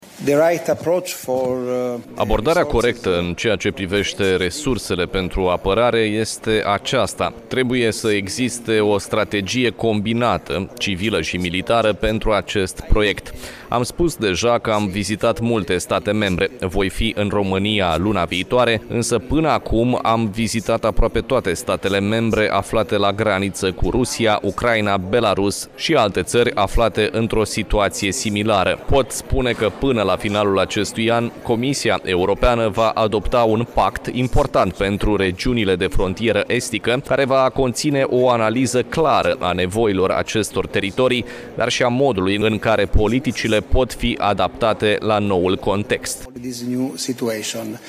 (AUDIO) Vicepreședintele Comisiei Europene, Raffaele Fitto, anunță un pact european pentru regiunile de frontieră, până la finalul anului